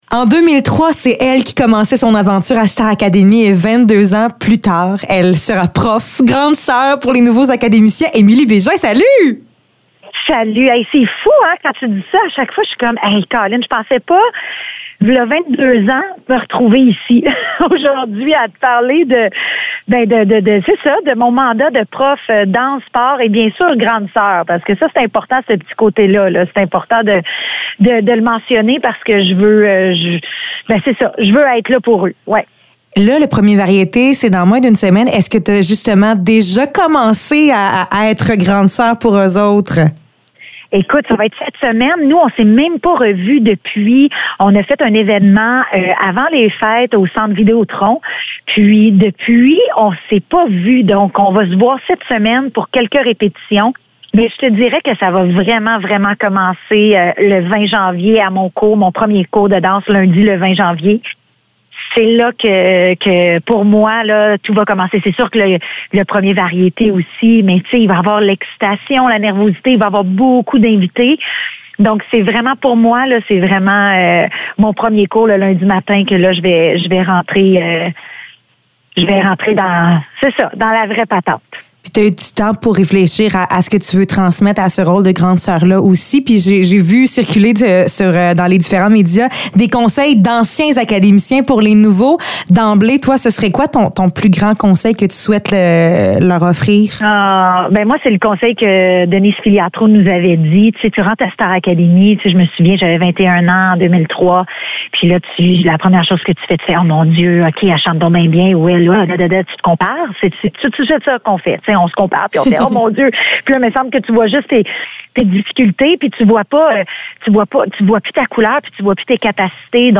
Entrevue avec Émily Bégin